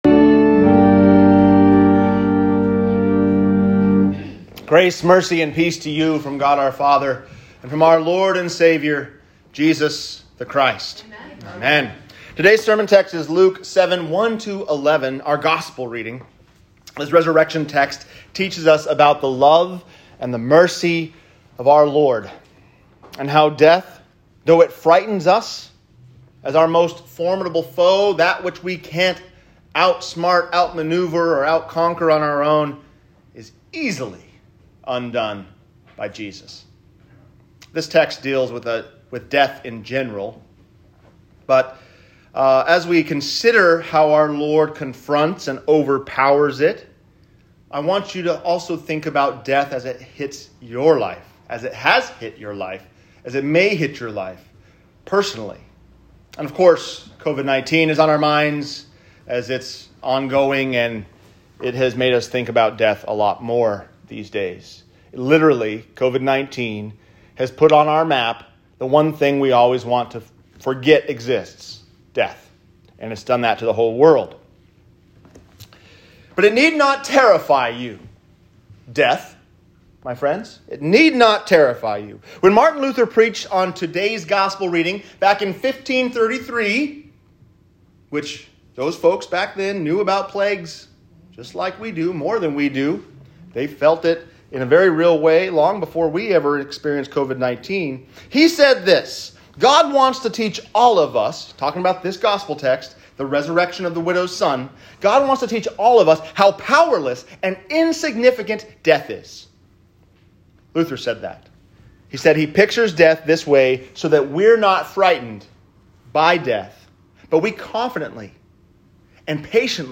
Death Is Powerless | Sermon